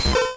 Cri de Rondoudou dans Pokémon Rouge et Bleu.